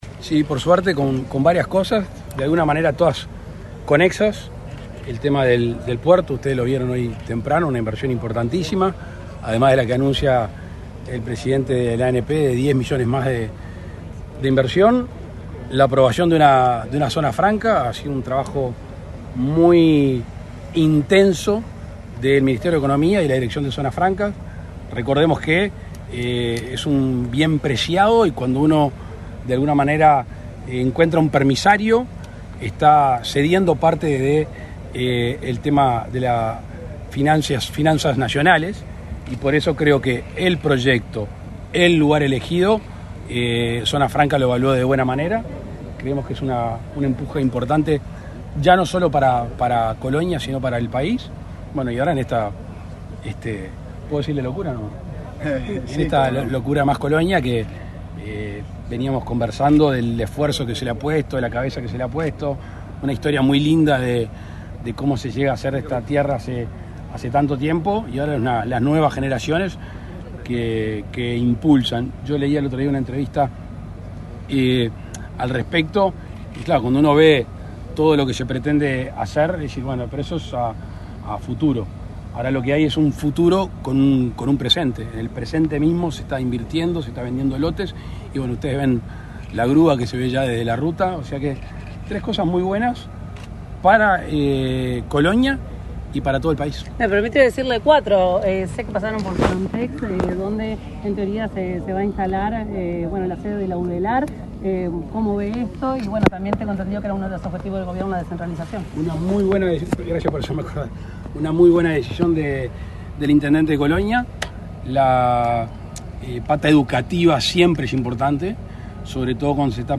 Declaraciones a la prensa del presidente de la República, Luis Lacalle Pou
Tras el evento, el mandatario realizó declaraciones a la prensa.